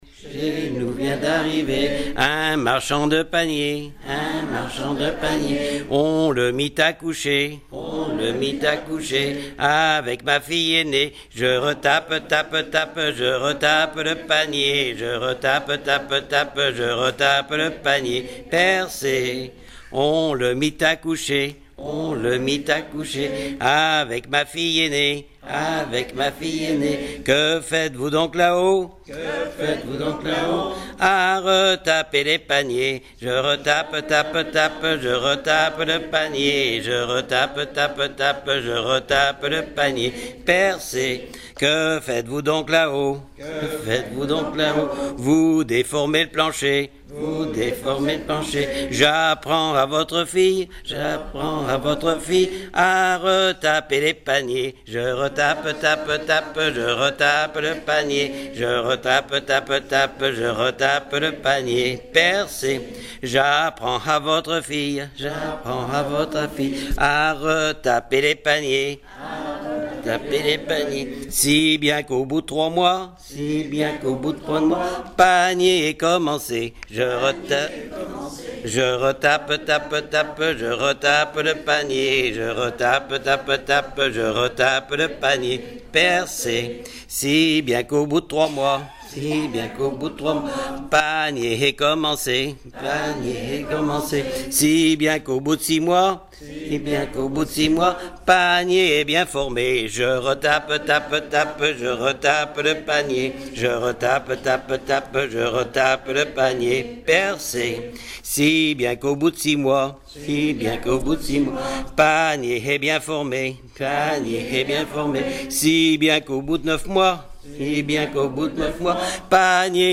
Région ou province Bocage vendéen
Genre laisse